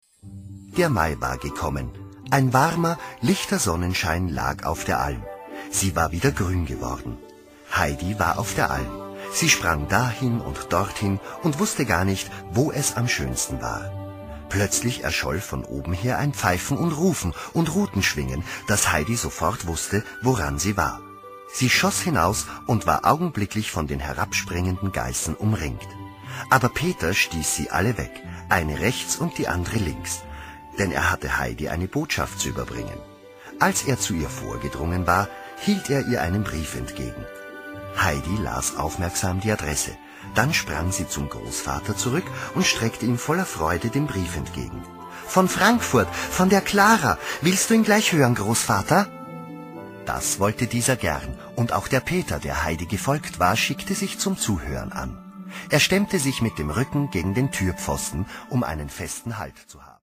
deutschsprachiger Profi-Sprecher, Regisseur, Schauspieler, Dozent, Coach / Konsulent f. Sprachgestaltung u. Dialogregie
wienerisch
Sprechprobe: Werbung (Muttersprache):